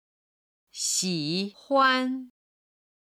ただ軽音部分の音源がないので、元々の単語の四声にしています。